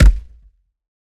punch.wav